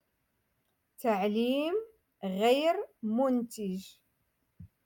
Moroccan Dialect-Rotation Five- Lesson Forty Nine